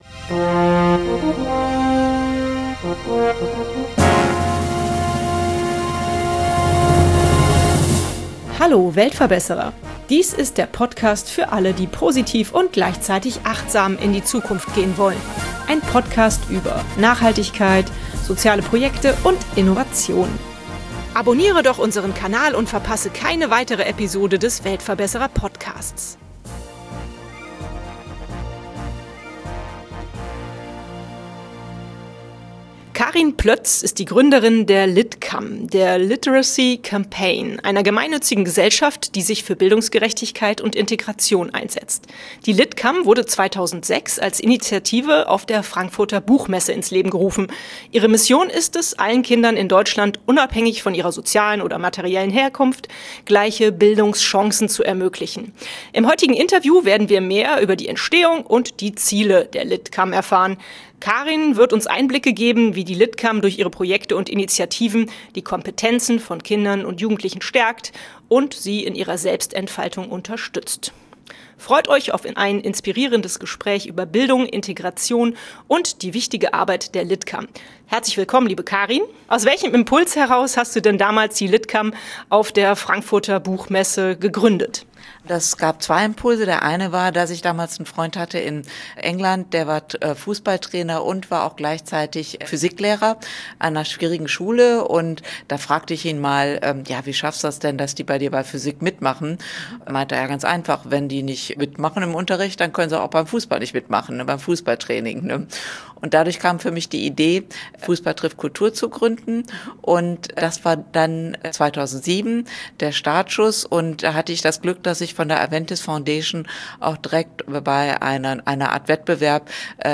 Ihre Mission ist es allen Kindern in Deutschland, unabhängig von ihrer sozialen oder materiellen Herkunft gleiche Bildung Chancen zu ermöglichen. Im heutigen Interview werden wir mehr über die Entstehung und die Ziele der LitCam erfahren.